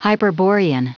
Prononciation du mot hyperborean en anglais (fichier audio)
Prononciation du mot : hyperborean